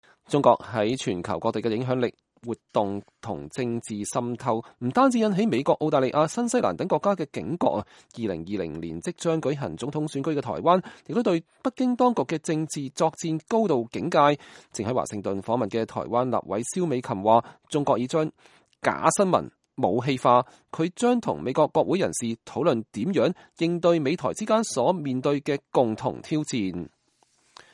蕭美琴會後受訪時表示，她此行的重要目的就是要和美國國會人士就如何對抗中國的政治滲透和立法管制的技術層面交換意見。